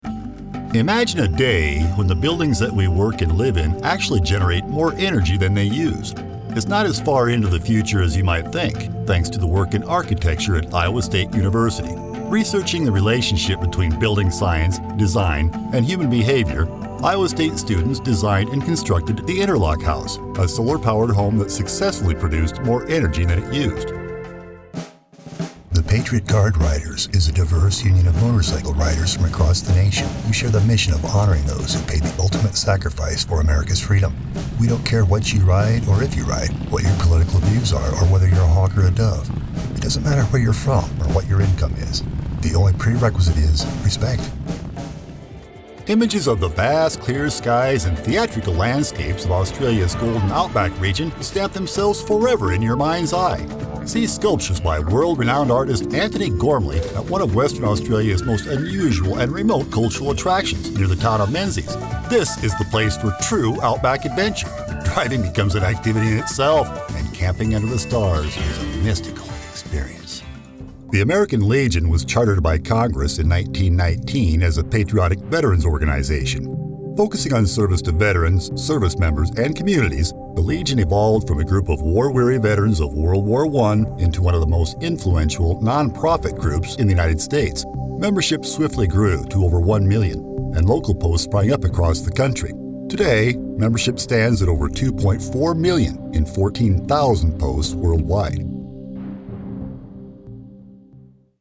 Deep, resonant, unique, genuine, masculine, weathered, authoritative, commanding, seasoned, distinctive.
middle west
Sprechprobe: Industrie (Muttersprache):